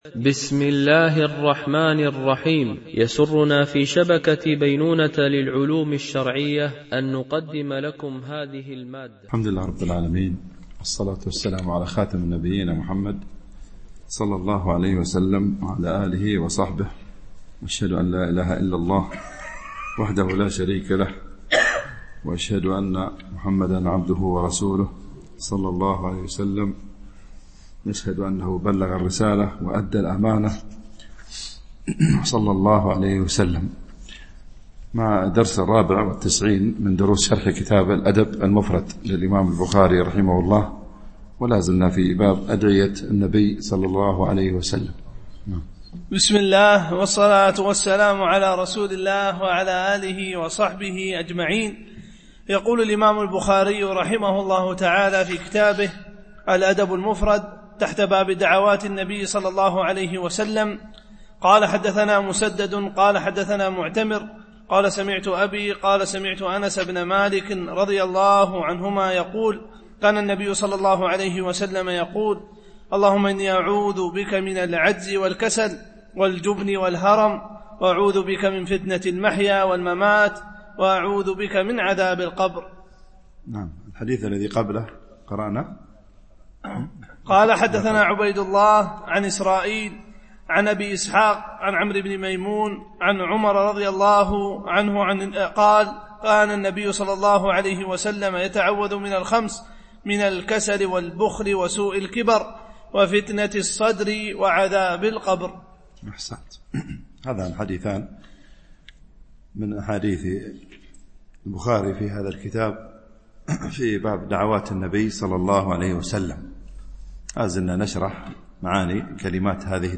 شرح الأدب المفرد للبخاري ـ الدرس 94 ( الحديث 671 – 679 )